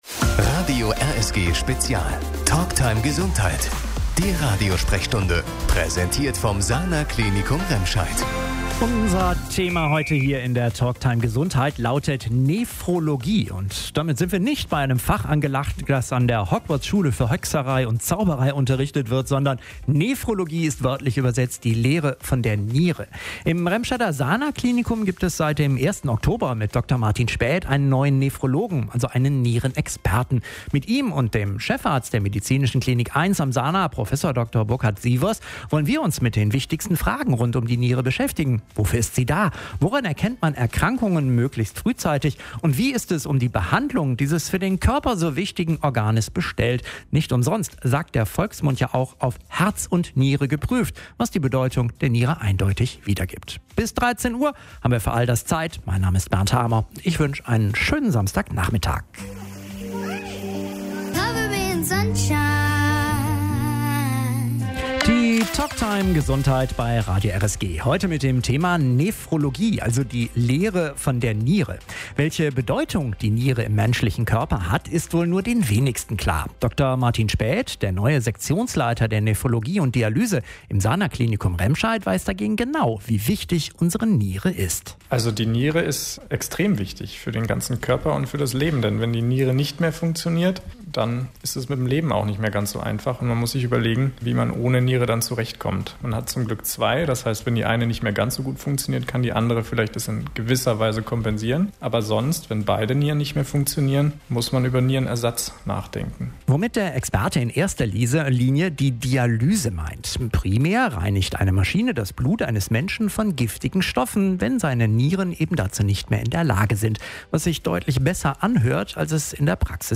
In der Radiosprechstunde am Samstag (11. Dezember) ging es diesmal um Nierenerkrankungen.